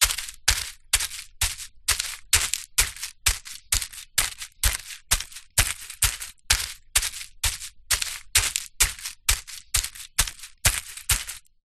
Звуки кенгуру
Звук прыжков кенгуру сквозь кусты